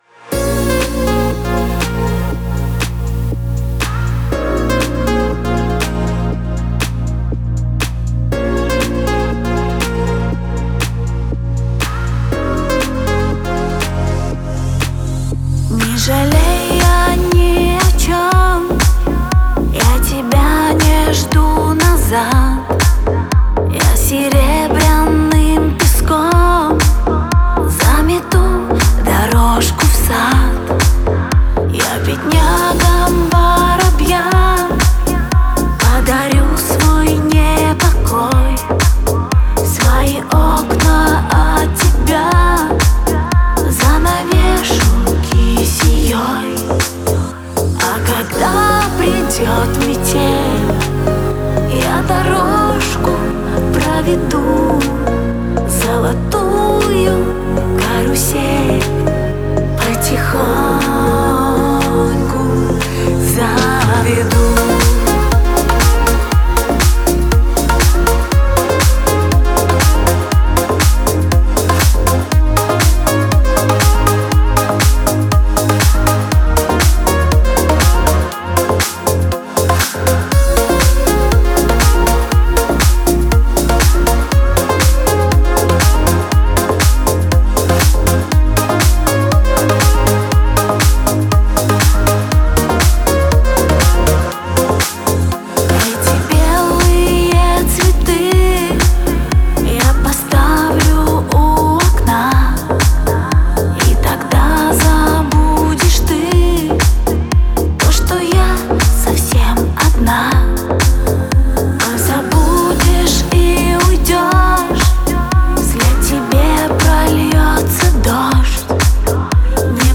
это трек в жанре электронной поп-музыки